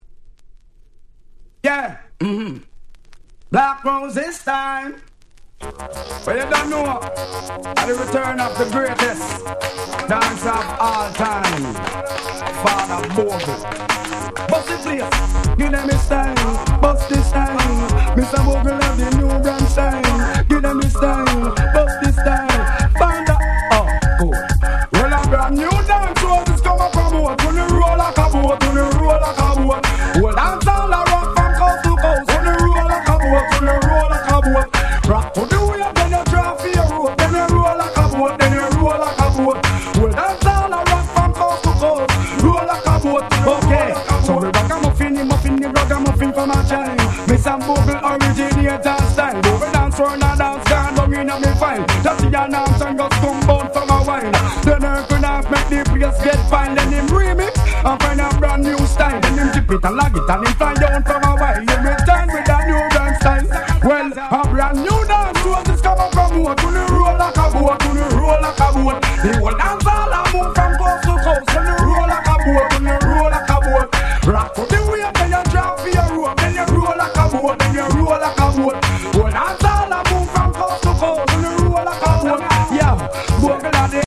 White Press Only Remix / Mash Up !!
Dancehall Reggae